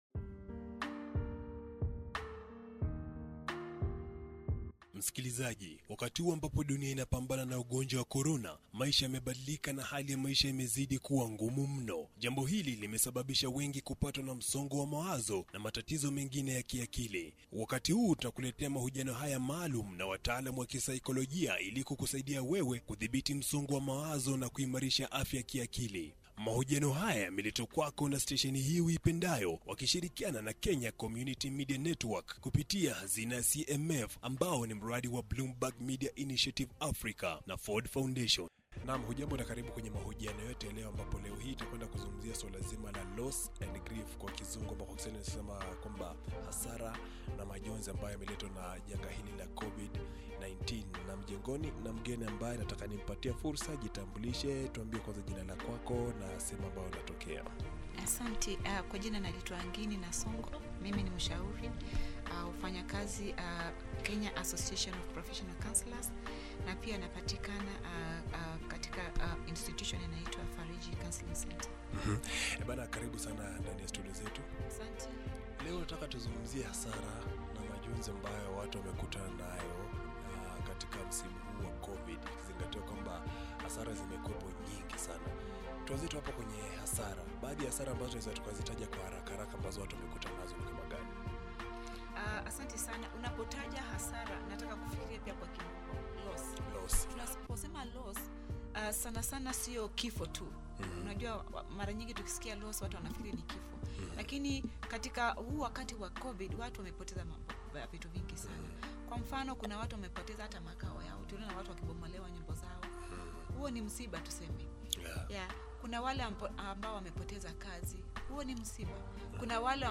Listen to this Swahili interview done by Koch FM